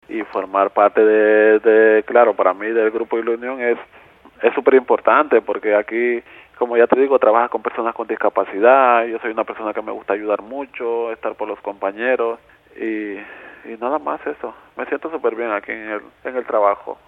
sin acritud formato MP3 audio(0,33 MB).